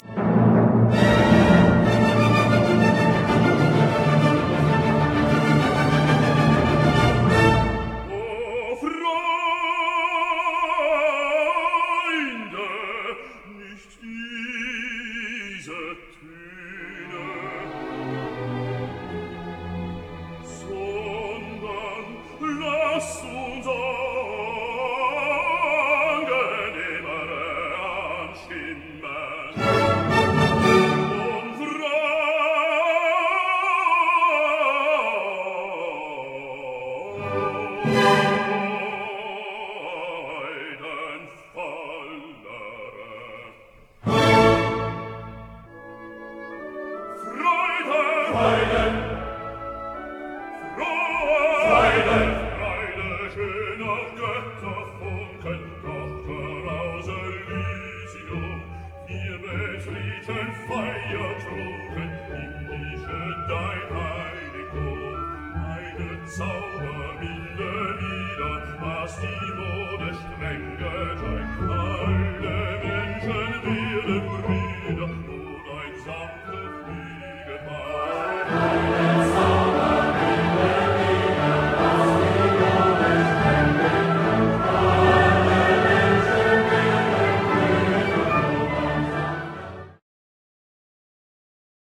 Key: d Minor Dedication: K�nig Friedrich Wilhelm III. von Preu�en Orch: 2 Fl., 1 Pic., 2 Ob., 2 Cl., 2 Bsn., 1 CBsn.
/ Soloists: S,A,T,B / Choir: S,A,T,B Approx.: 68 Min.